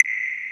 DDWV POP SNAP 1.wav